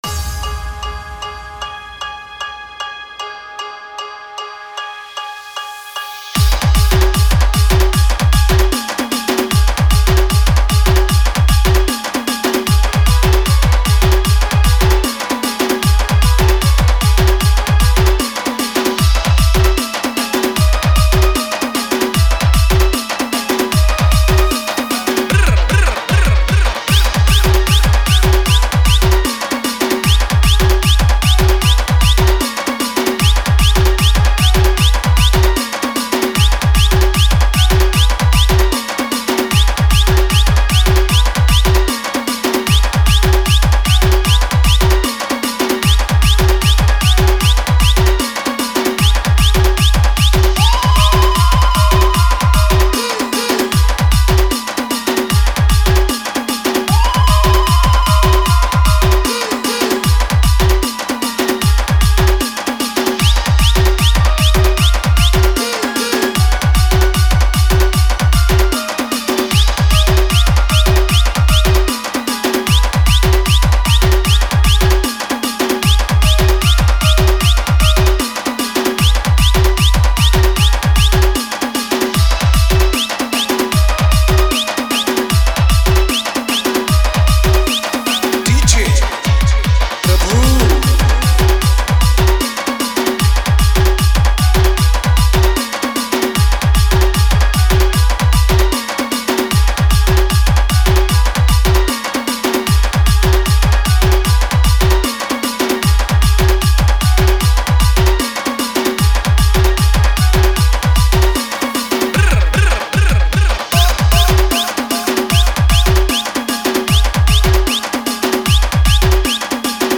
MATAL DANCE